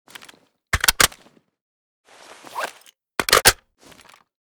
rpk_reload.ogg